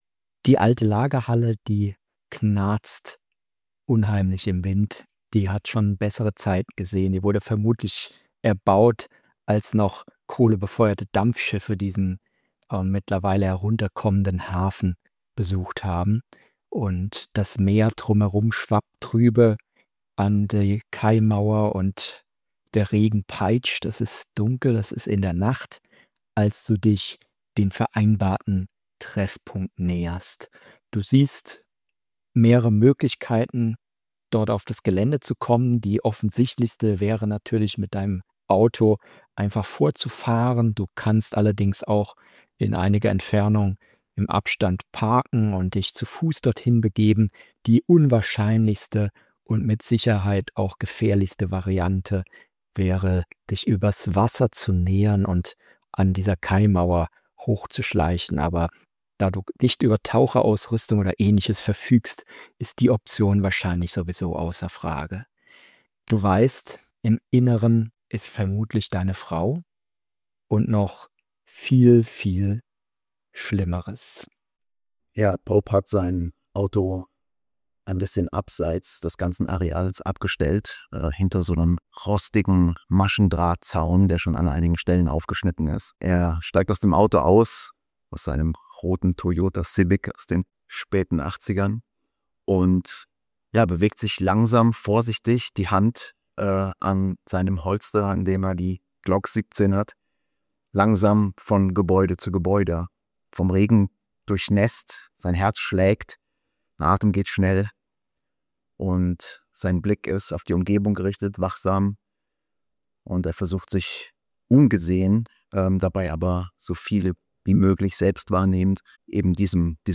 Diese Folge beginnt nicht mit Theorie – sondern mit einer Szene. Eine intensive Delta-Green-Spielsequenz zieht uns mitten hinein in Angst, Entscheidungsmomente und nackte Anspannung.